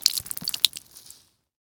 blood.ogg